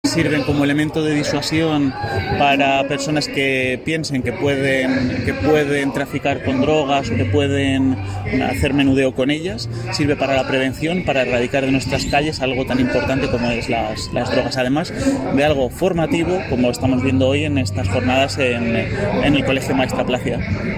Declaraciones del alcalde Miguel Óscar Aparicio